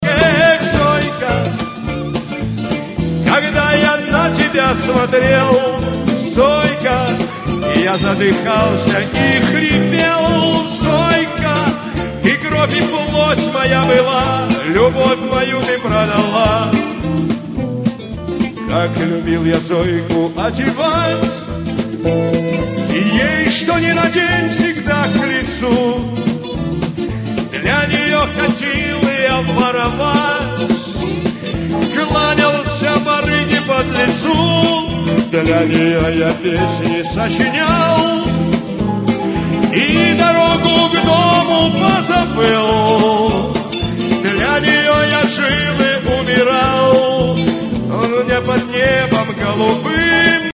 • Качество: 320, Stereo
гитара
блатные
дворовые